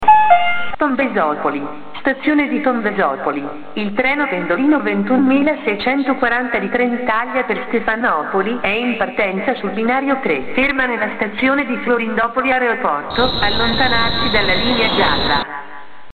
Ciò ci consentirà di attivare il modulo sonoro della Faller ed ascoltare gli annunci che avremo programmato.
attiva l'annuncio in stazione;